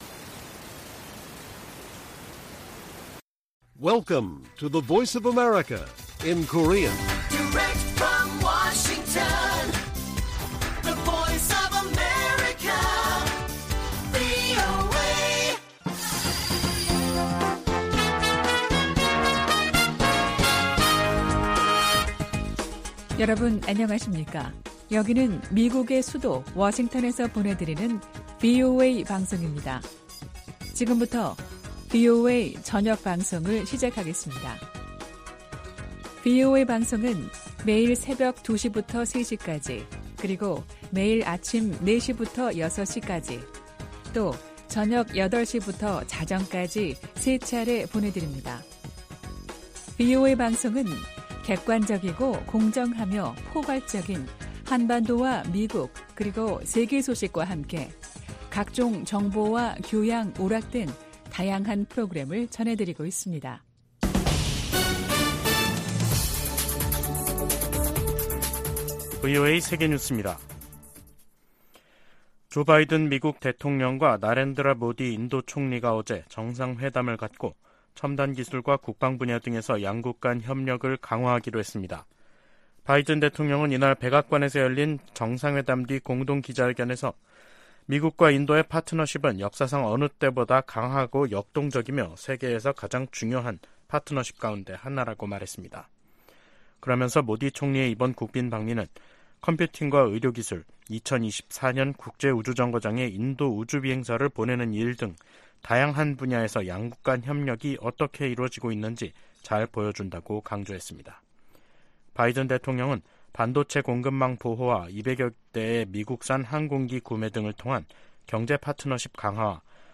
VOA 한국어 간판 뉴스 프로그램 '뉴스 투데이', 2023년 6월 23일 1부 방송입니다. 북한이 앞으로 몇 년동안 핵무기를 강압 외교에 활용할 가능성이 높다고 미국 정보당국이 전망했습니다. 조 바이든 미국 대통령과 나렌드라 모디 인도 총리가 북한의 미사일 발사를 규탄하고 한반도 비핵화 약속을 재확인했습니다. 한국 정부는 북한이 젊은 여성과 소녀를 내세워 체제 선전을 해 온 유튜브 채널들을 차단했습니다.